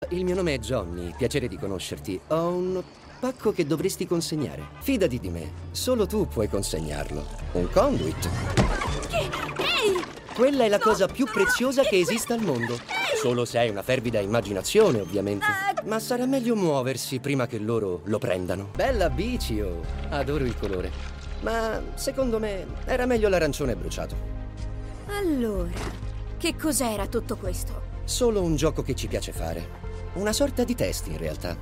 nel cartone animato "Secret Level"